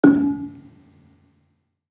Gambang-D#3-f.wav